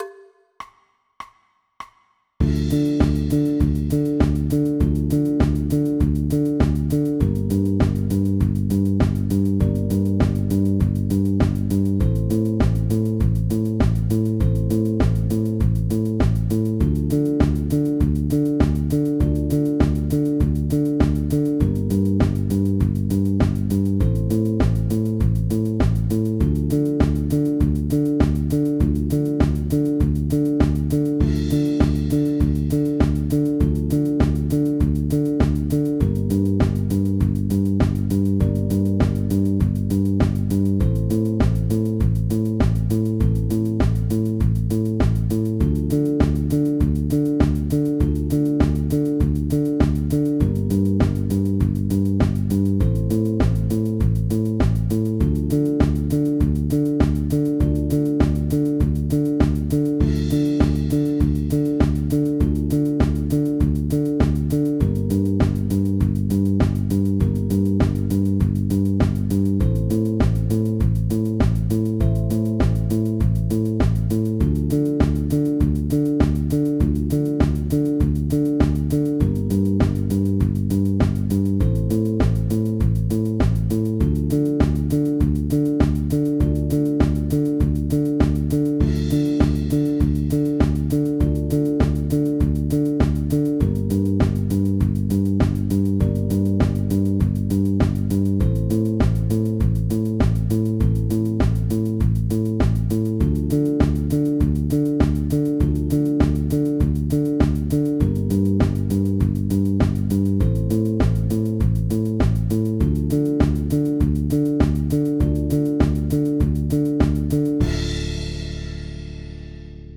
Download Zeg Maor Moi (instrumentaal toonsoort Dm)